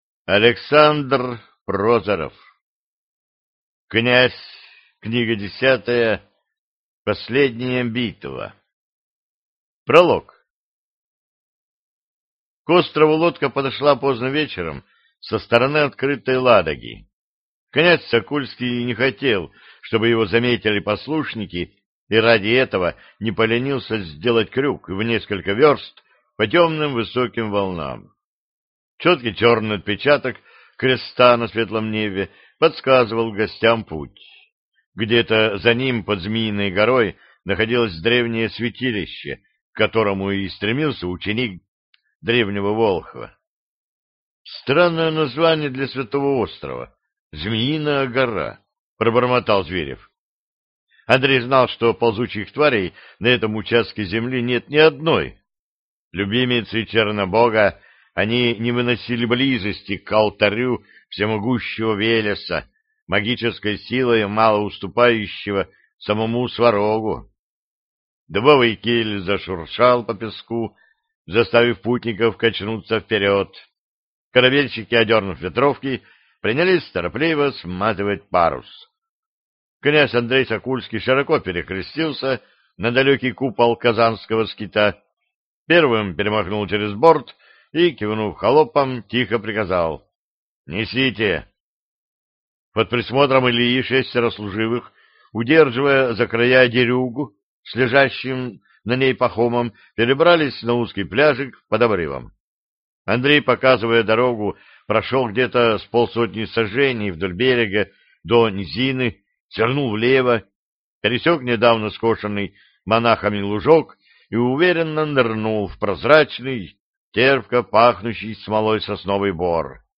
Аудиокнига Последняя битва | Библиотека аудиокниг